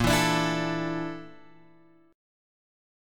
A# Major 7th Flat 5th